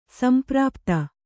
♪ samprāpta